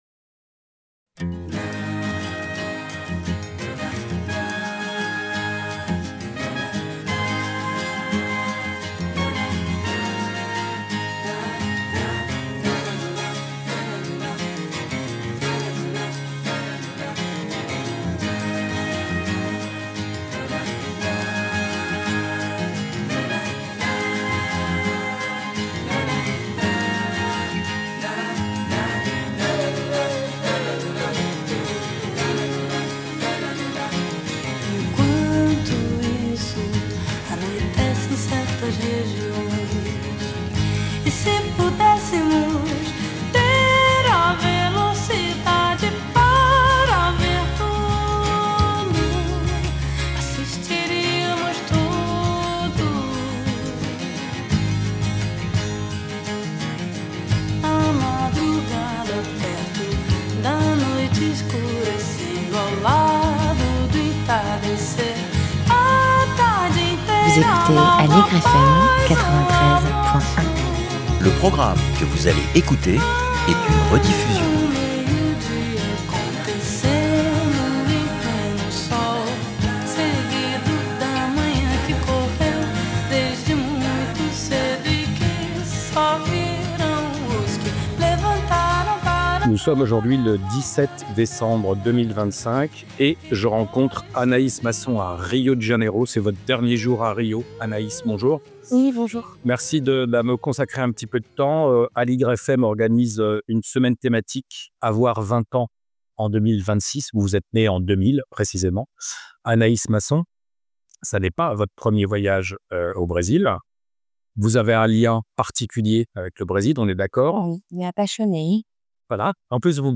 Interview enregistrée le 17 décembre 2025, diffusée vendredi 16 janvier sur Aligre FM et en podcast.